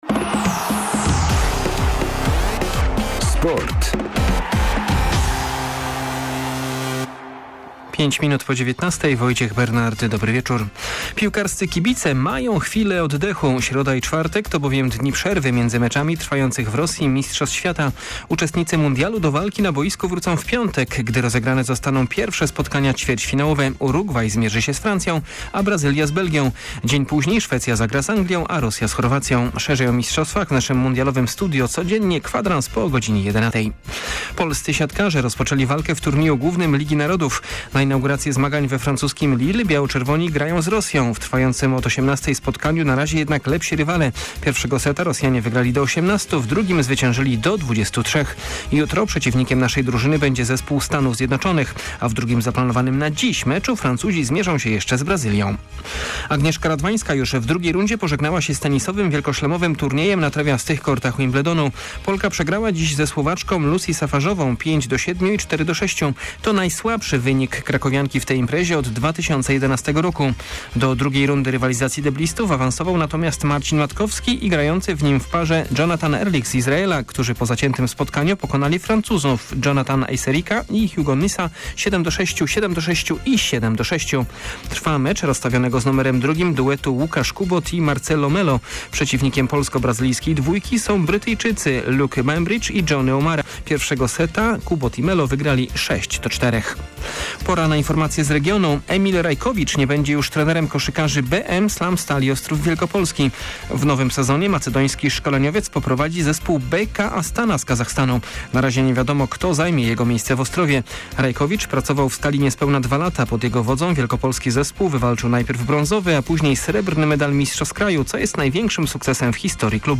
04.07 serwis sportowy godz. 19:05